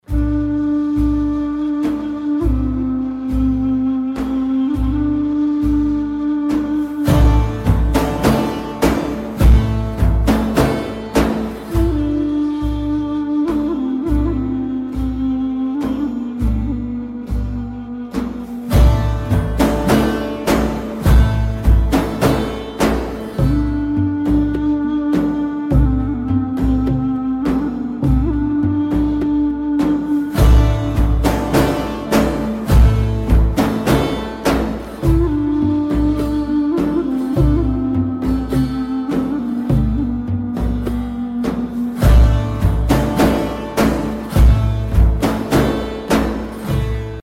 زنگ موبایل (بی کلام) ملایم
با سبک ایرانی_استانبولی